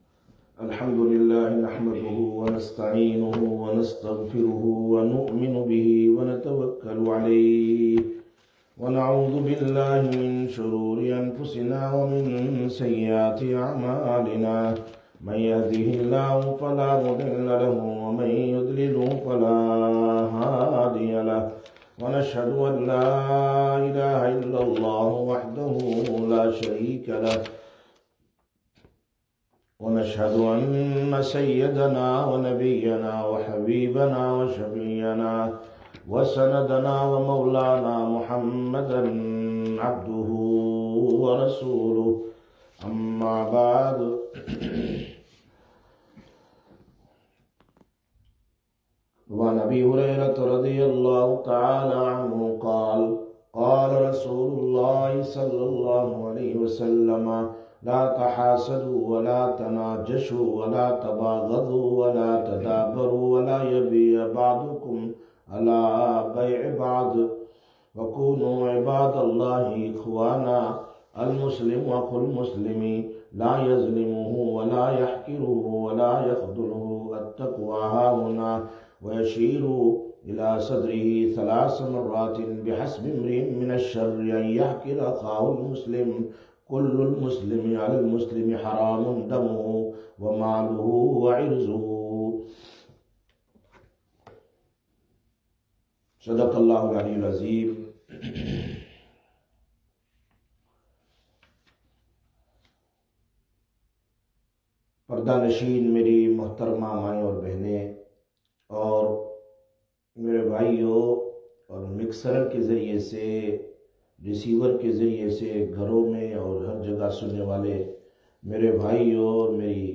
03/11/2021 Sisters Bayan, Masjid Quba